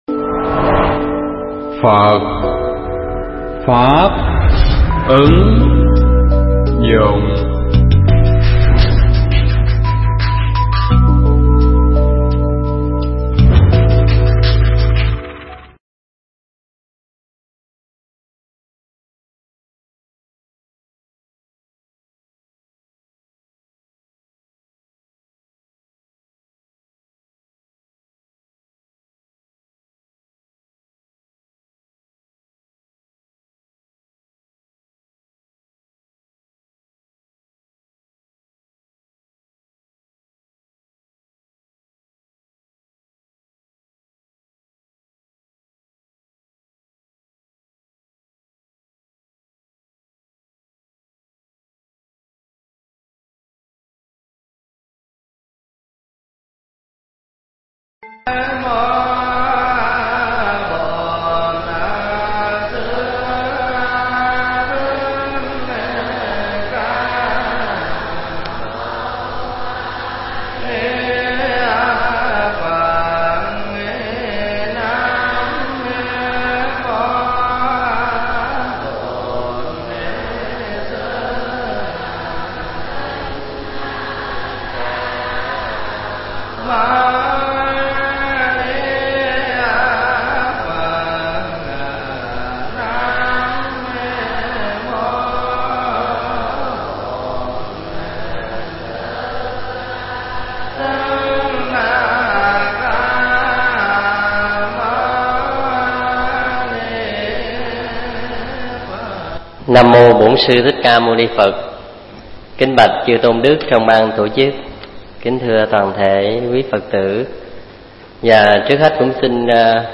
Pháp thoại Tìm Hiểu Vài Đặc Điểm Kinh A Di Đà
giảng tại chùa Hoằng Pháp trong khoá tu Phật Thất lần thứ 37